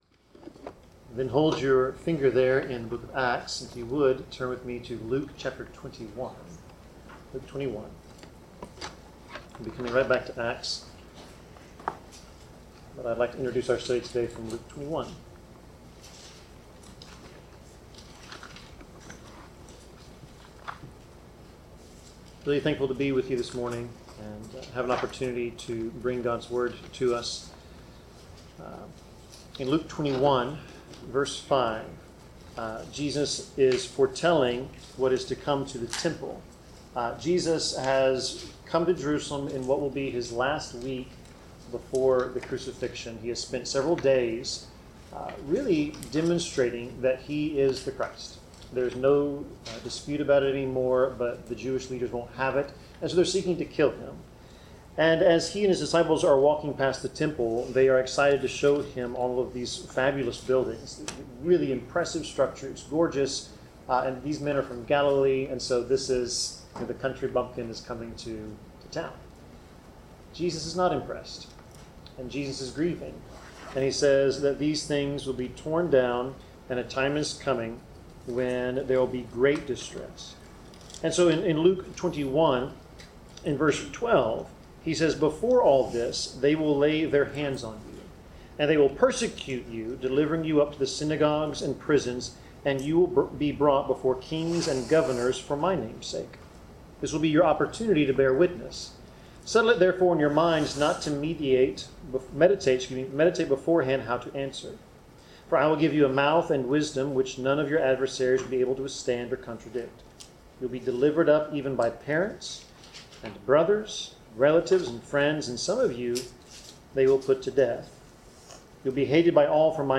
Passage: Acts 23-26 Service Type: Sermon